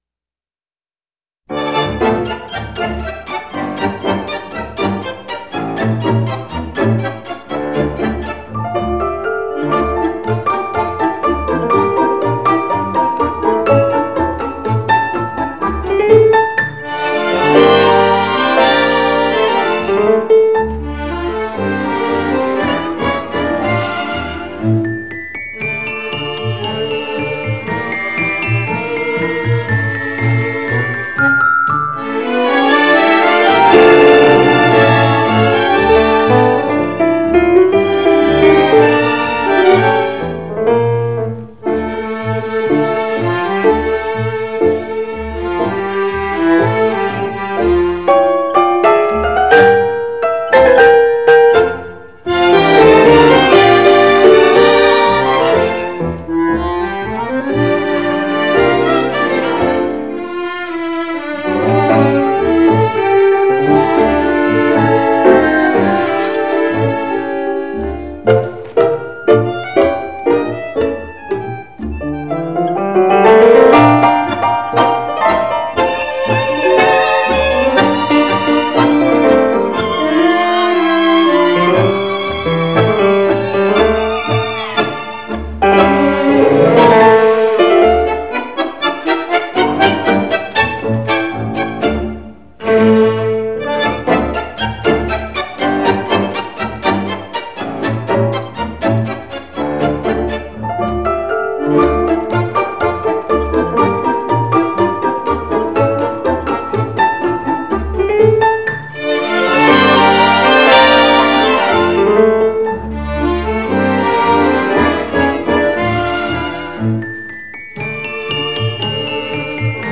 Genre: Tango, Latin